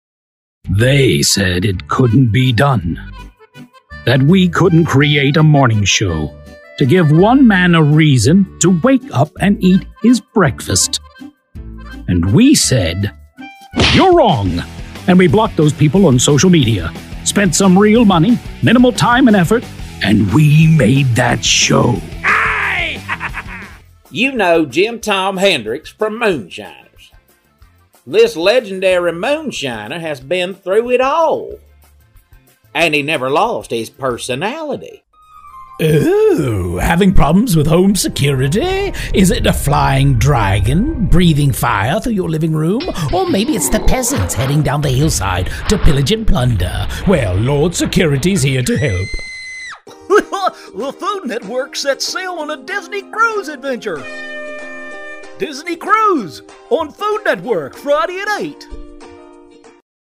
VOICE
Voice: Baritone
Accents: American (Very Good), Appalachian American (Very Good), British (Very Good), Irish (Good), Scottish (Good), Indian (Good)
Voice Character: Colorful/Warm/ Commercial VO/ Animated / Film/Rich/Pleasant